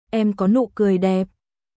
エム コー ヌー クオイ デップ🔊